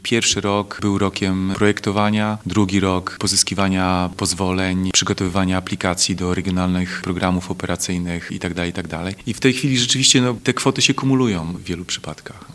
– Ich przygotowanie, na co wpływ mają także tak zwanne czynniki zewnętrzne, sporo trwało, wreszcie w połowie kadencji będziemy mogli je rozpoczynać – mówi burmistrz Robert Krupowicz.